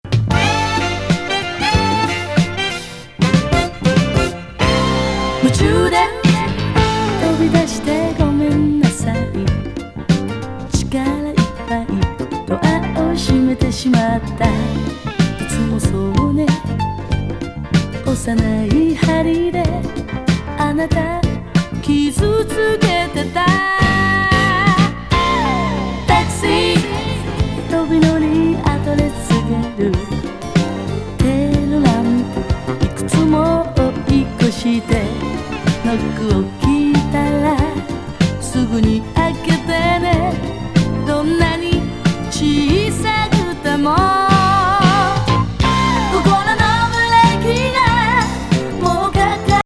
ホッコリ・シティ・グルーヴ